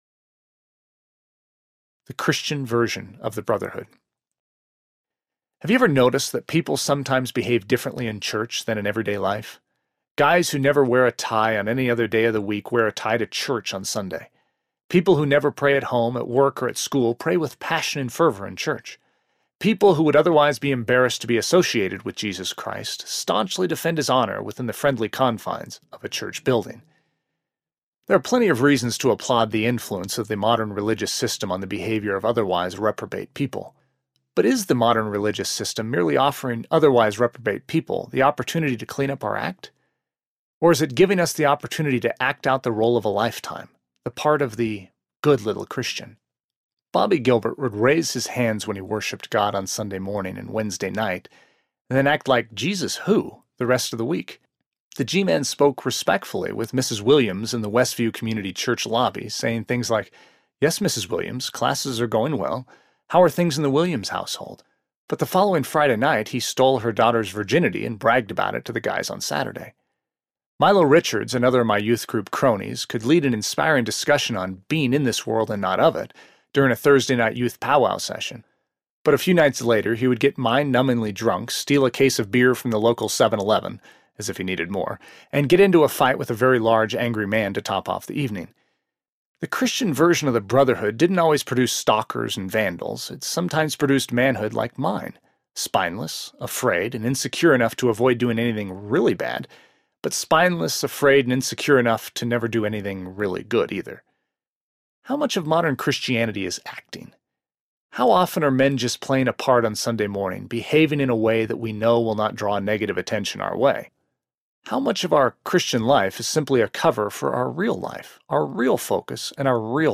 God’s Gift to Women Audiobook